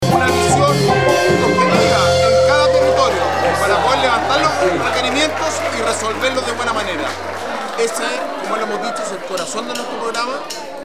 Alcalde-Sebastian-Alvarez-lo-esencial-del-trabajo-territorial-.mp3